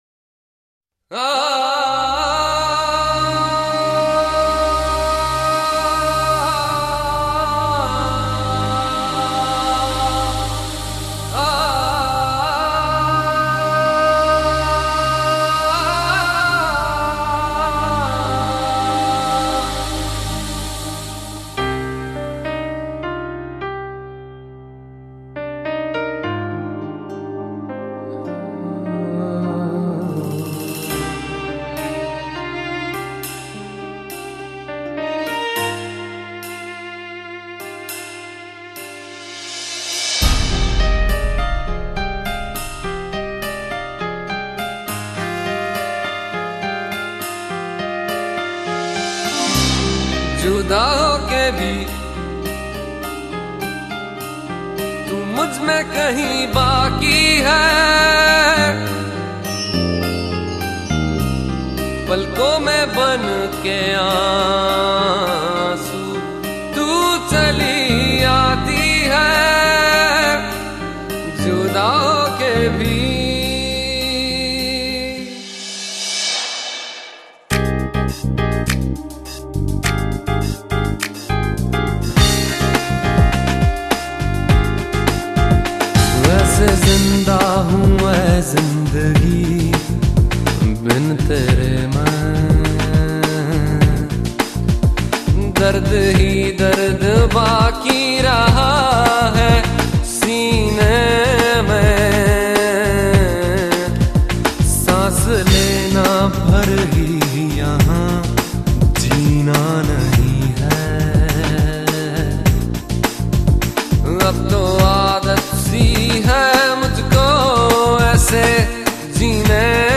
Bollwood Songs